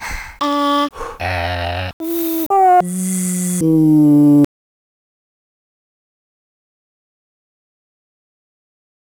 werkze 1 phonetic typewriter werkze 1 beitrag von